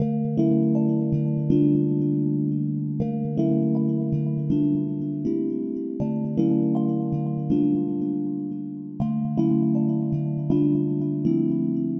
Tag: 80 bpm Chill Out Loops Synth Loops 2.02 MB wav Key : A